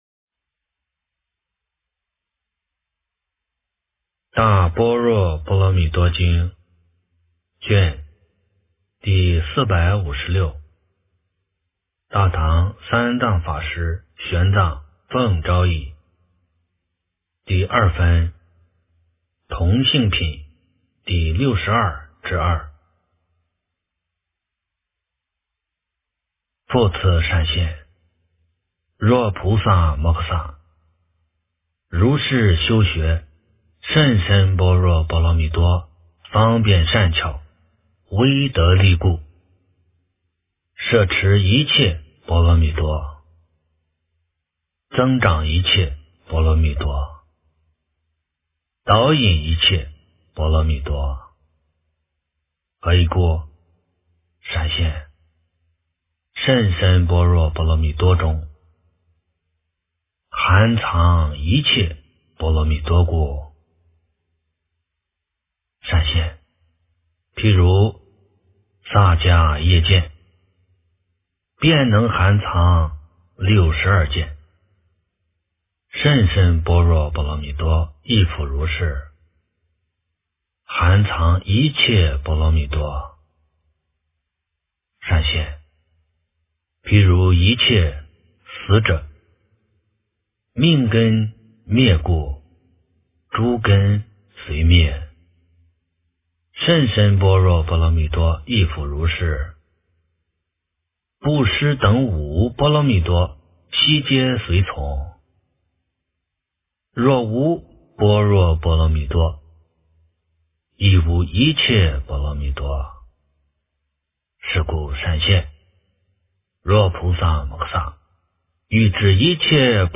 大般若波罗蜜多经第456卷 - 诵经 - 云佛论坛